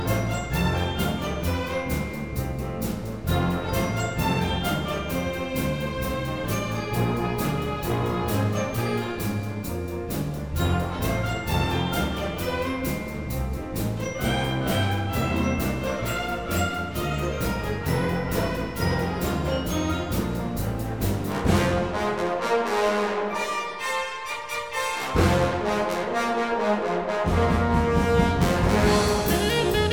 Жанр: Музыка из фильмов / Саундтреки
# Soundtrack